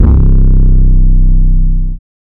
REDD BEST 808 2.wav